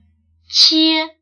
收录世界范围不同母语背景说话人说汉语时的口音表现（汉语语音偏误，包括文字描述与有声语料），并提供与汉语语音偏误有关的资料、资源。
口音（男声）